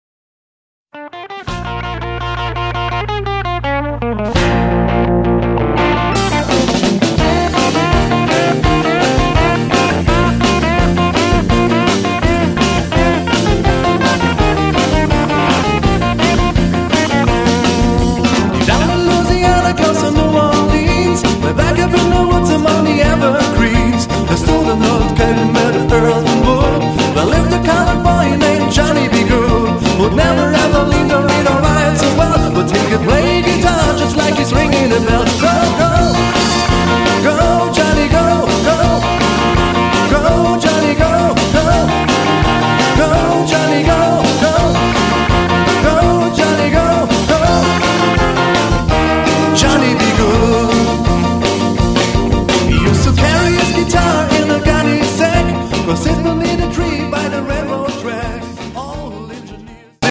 Tanz-& Unterhaltungskapelle